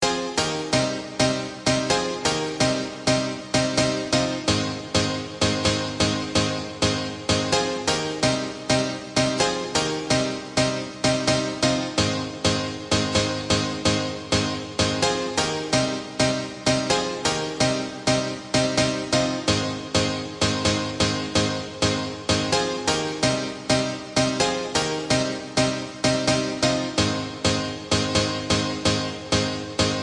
标签： 循环 钢琴 旋律 周围环境 128BPM 气氛 DancePiano 气氛
声道立体声